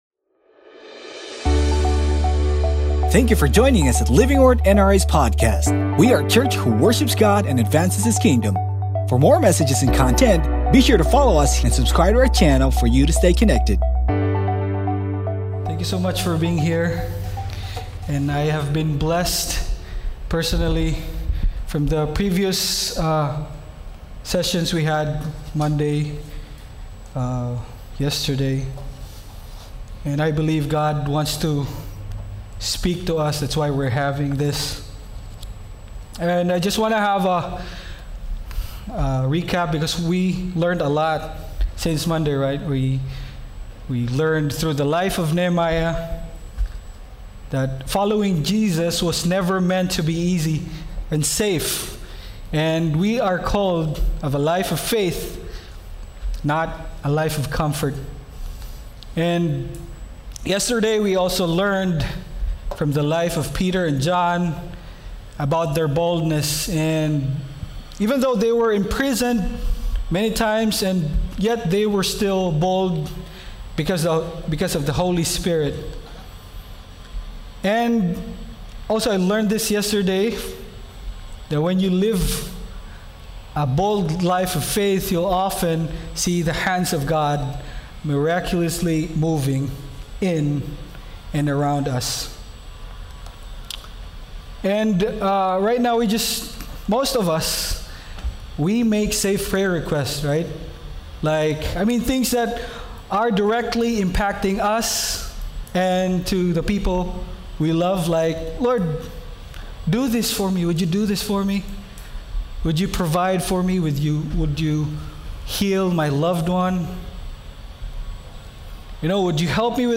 Sermon Title: SEND ME Scripture Text: ISAIAH 6:1-8